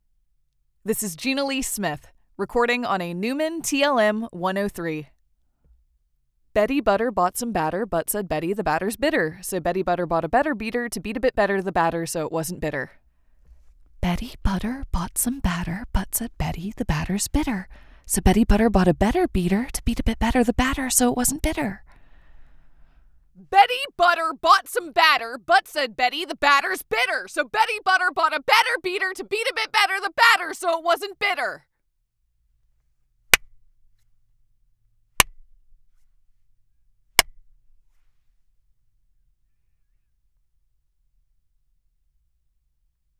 MICROPHONE. Neumann TLM 103, Warm Audio WA87 R2
INTERFACE. Solid State Logic SSL2+
LA VOCAL BOOTH.
Home Studio Specs
Bright!
Warm, textured, genuine,